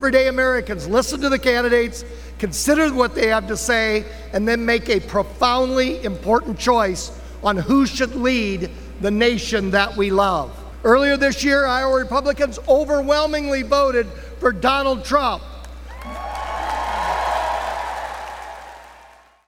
Iowa G-O-P chairman Jeff Kaufmann was chosen to kick off the process with a nominating speech.